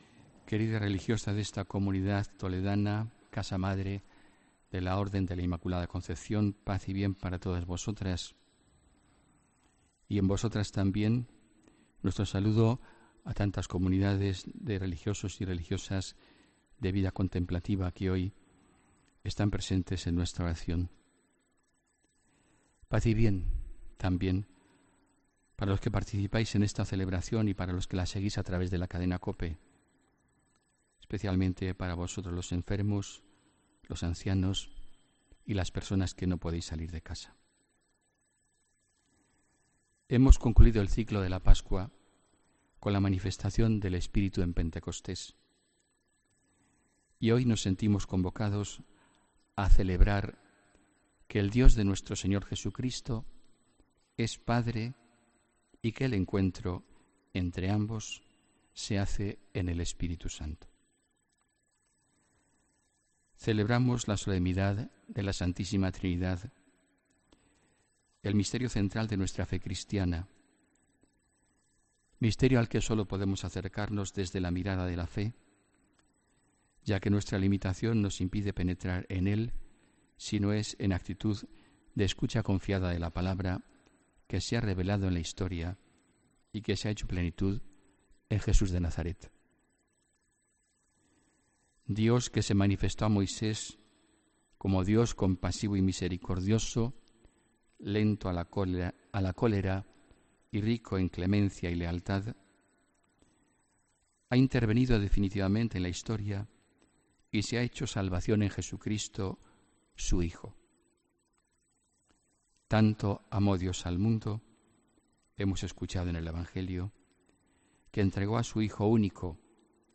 Homilía 11 de junio de 2017